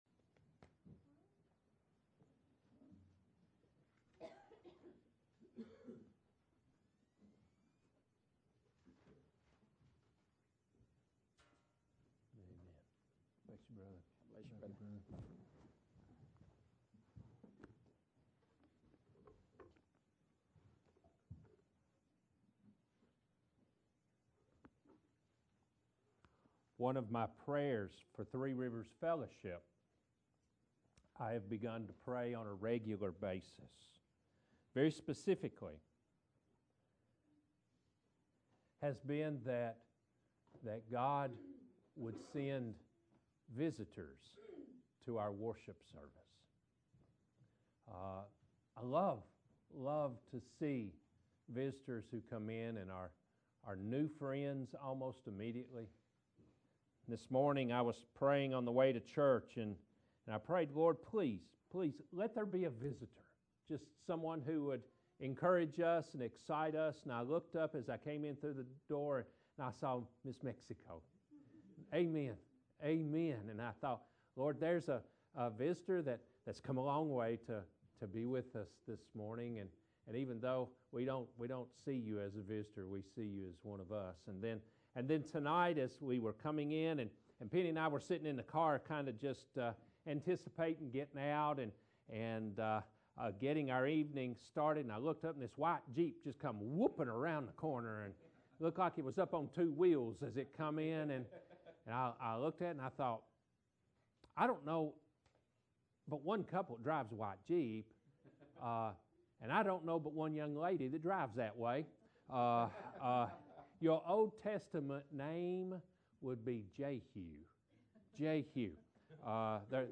41-47 Service Type: Evening Worship « Spiritual Disciplines